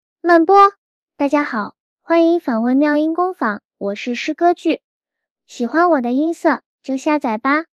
所以这几天放假就给大家制作了一个萝莉音色的诗歌剧模型。